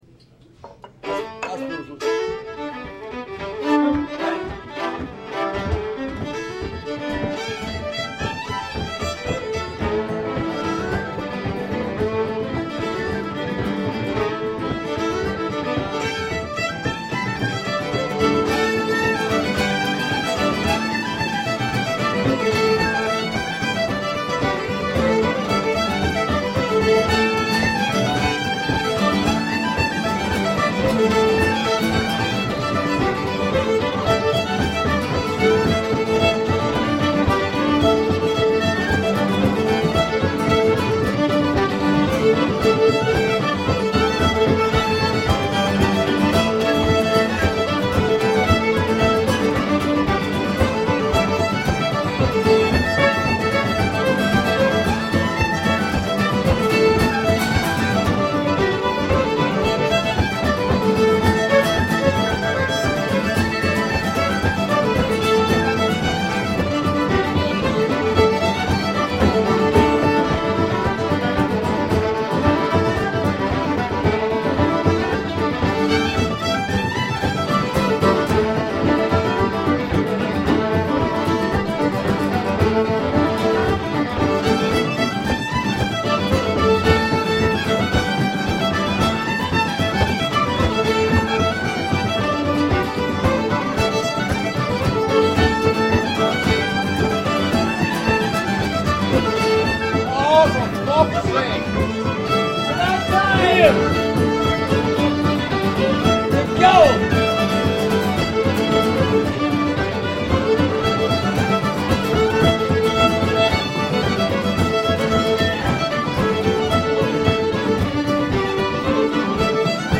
What a great sesh at the Banshee last week.
The place filled up early, and we warmed up with these jigs.